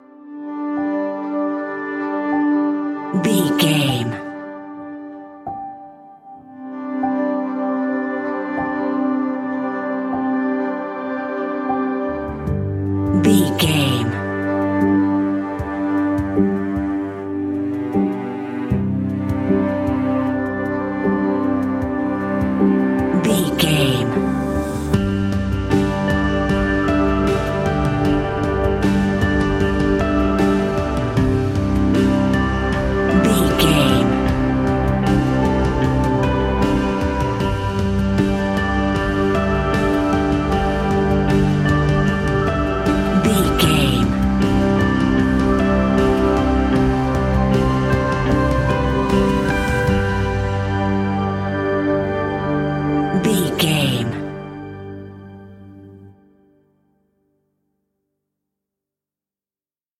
In-crescendo
Ionian/Major
Slow
dreamy
melancholy
mellow
cello
electric guitar
piano
strings